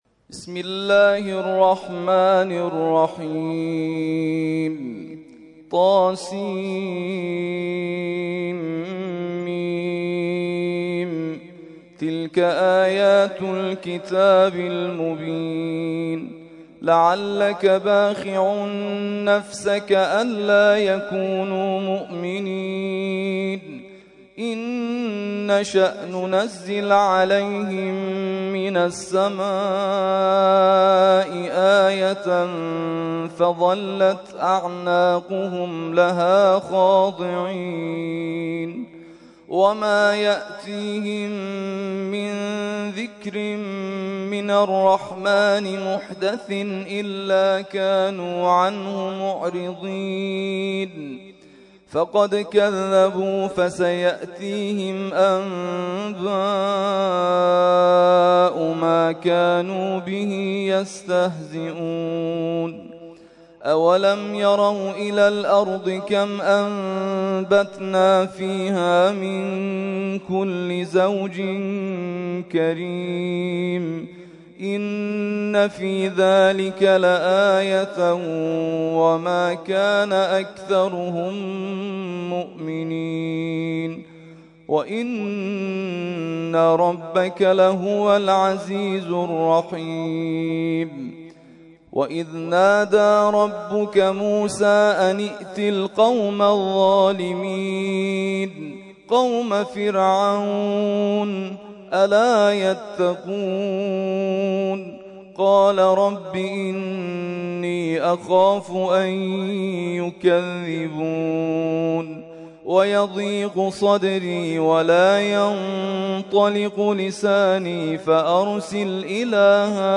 ترتیل خوانی جزء ۱۹ قرآن کریم در سال ۱۳۹۴